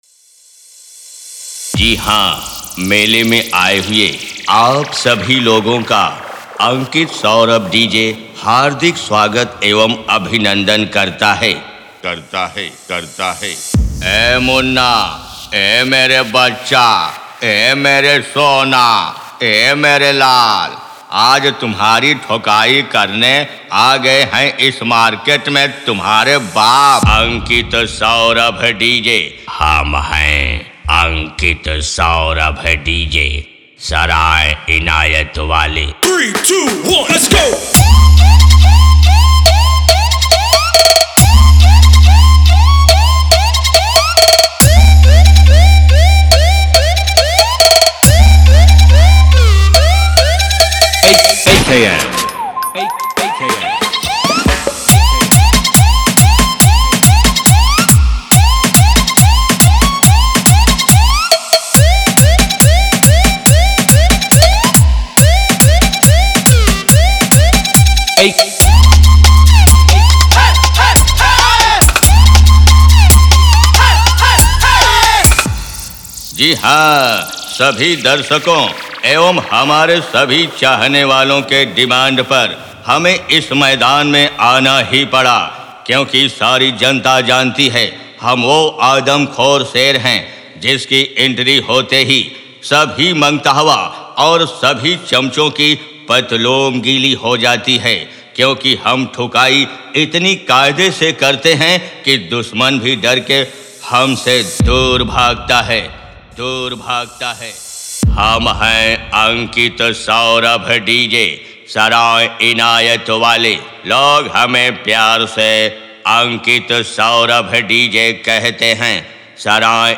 Competition DJ Mix
Dialogue DJ Beat, Bass Boosted Mela Remix